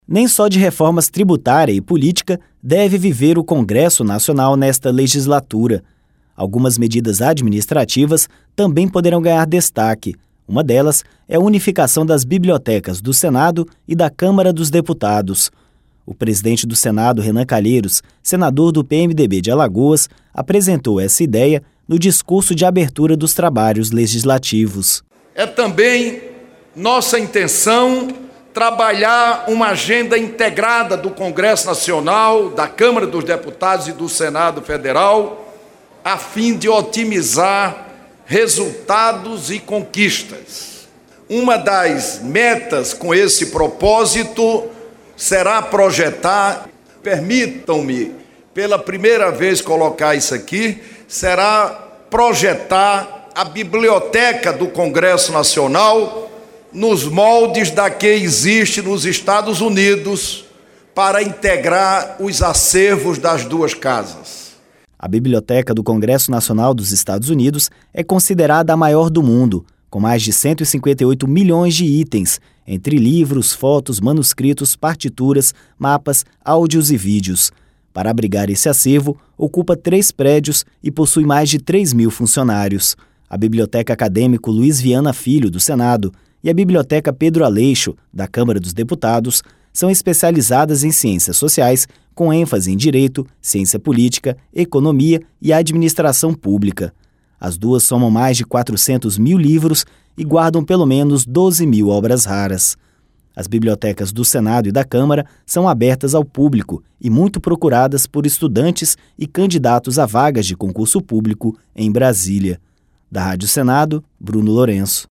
Mais informações com o repórter